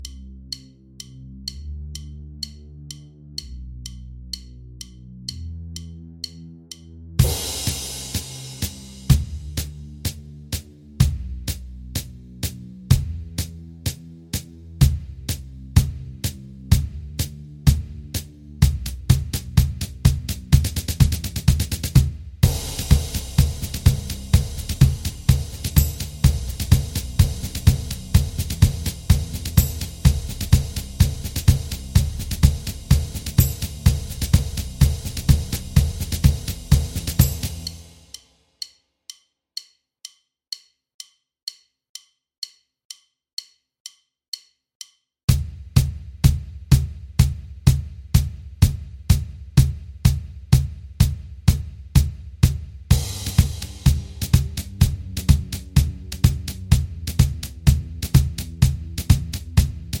Minus Main Guitars For Guitarists 3:47 Buy £1.50